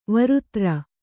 ♪ varutra